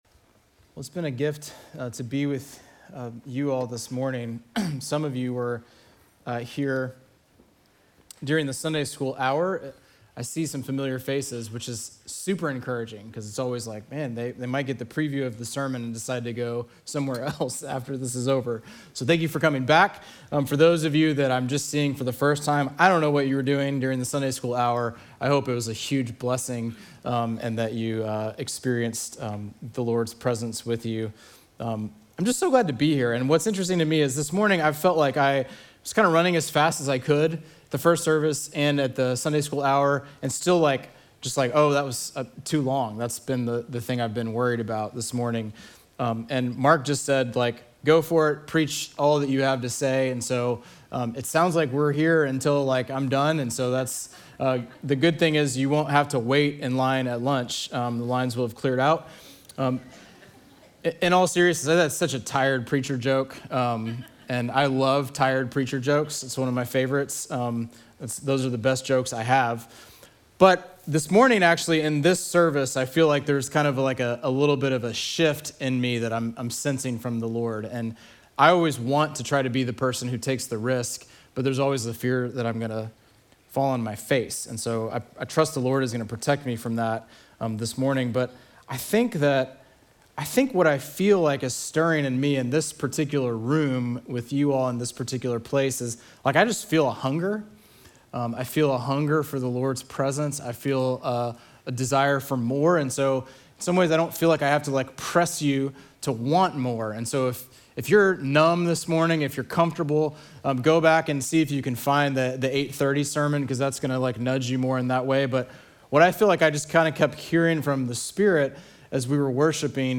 Sermon text: John 14:12-18